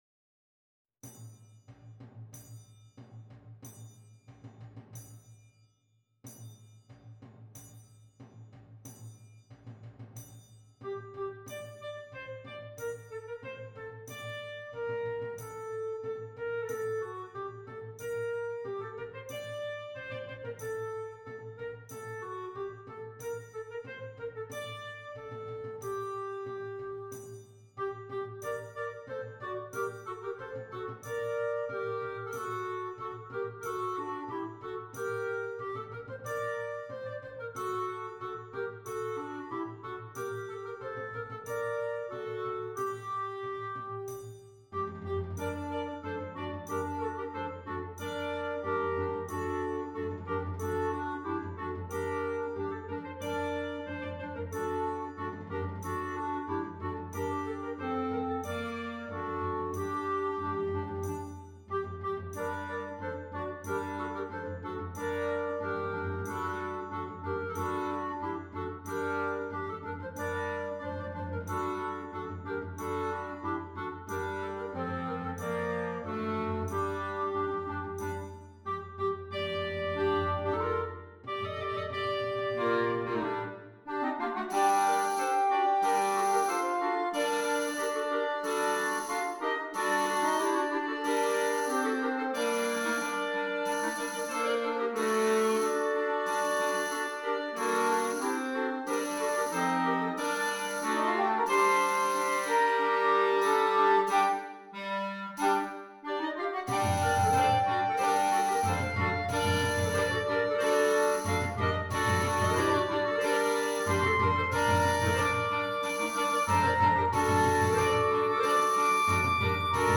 6 Clarinets
Traditional French Carol